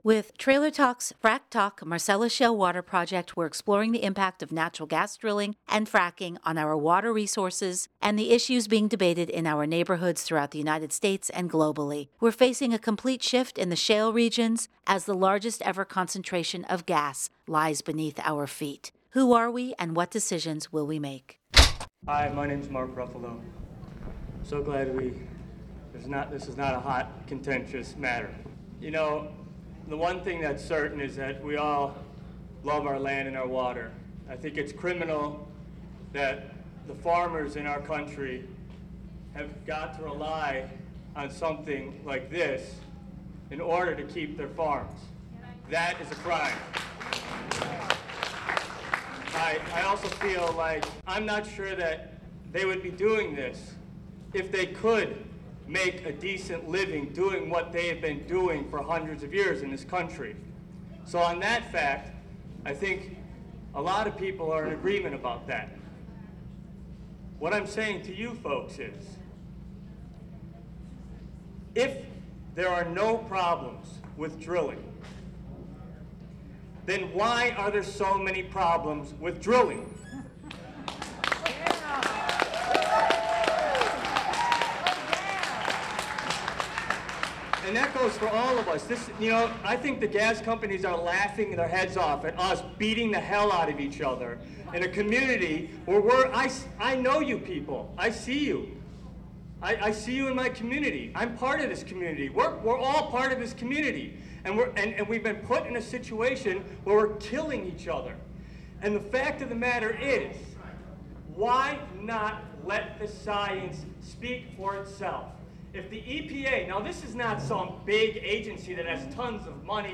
DRBC Gas Drilling Hearing, Trenton, NJ - 7/14/2020
Mark Ruffalo gives his testimony and addresses the audience during the Delaware River Basin Hearings in Trenton, New Jersey on July 14, 2010.
ShaleProjectMarkRuffaloDRBCHearingTrentonNJ7-14-10.mp3